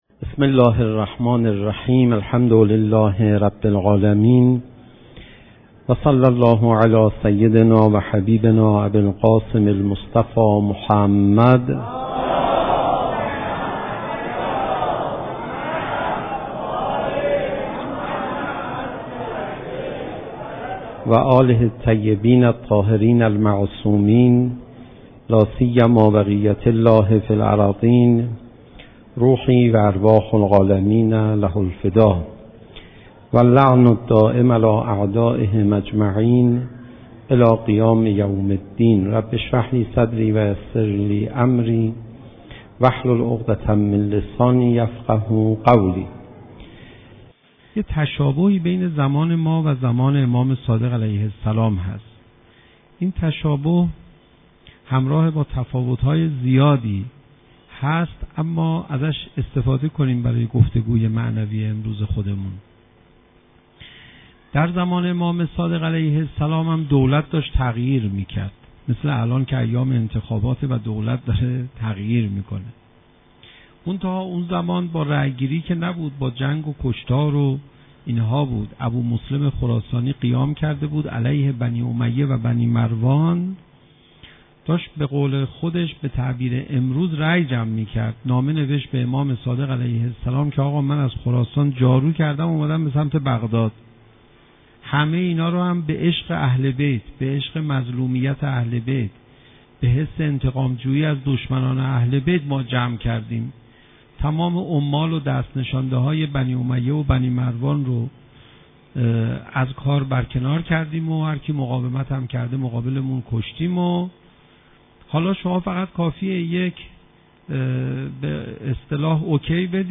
صوت | نقش مردم در حکومت (تهران - مسجد حضرت ولی‌عصر(عج))